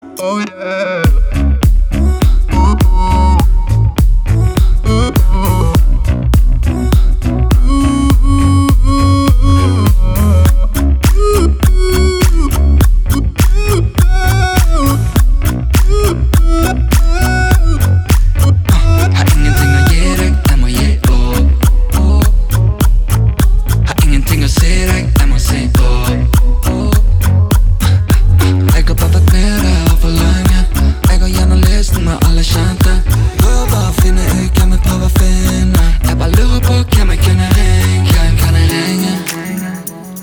• Качество: 320, Stereo
мужской вокал
Dance Pop
электронный голос
тиканье часов
Весёлый норвежский трек.